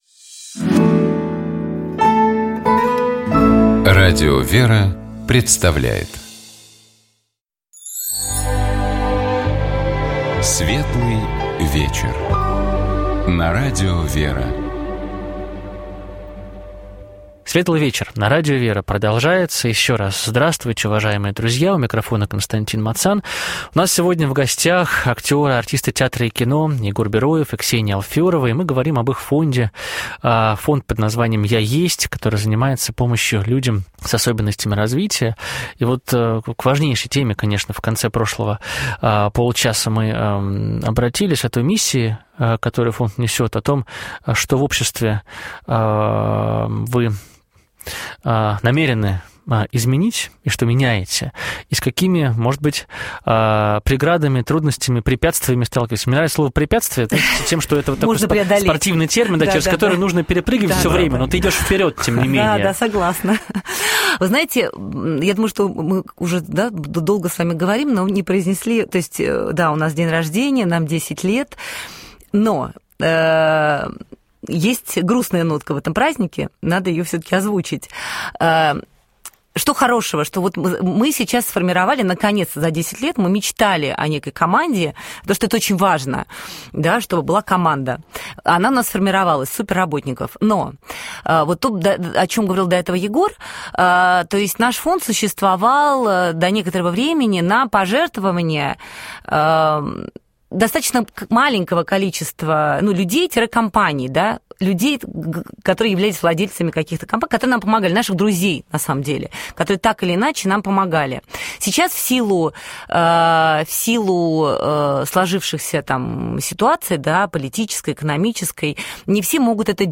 У нас в гостях были артисты театра и кино Егор Бероев и Ксения Алферова.